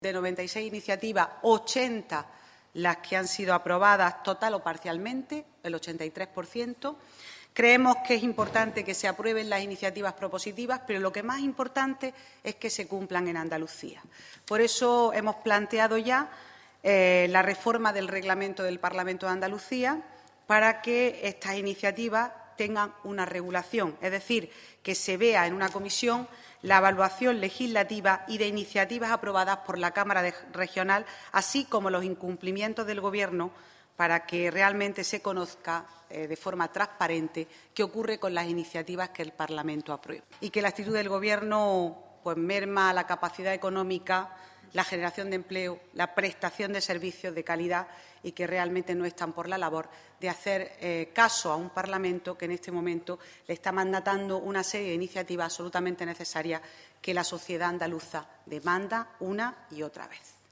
Escuche las declaraciones: